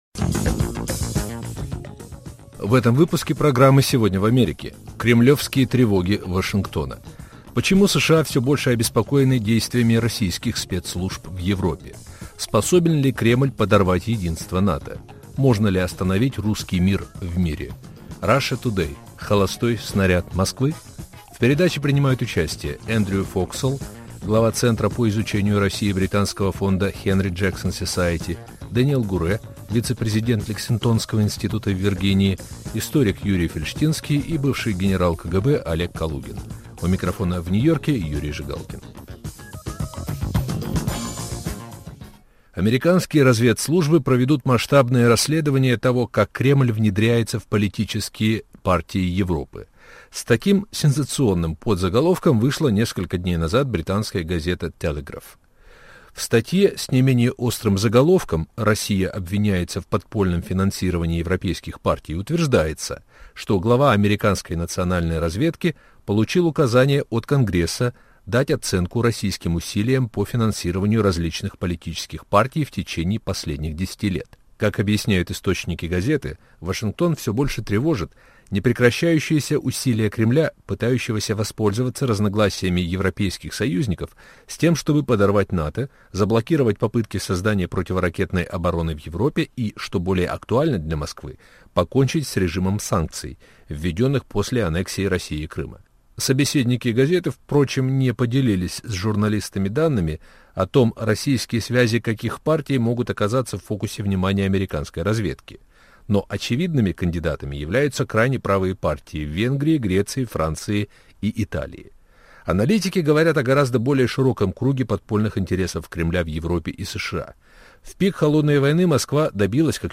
обсуждают эксперты.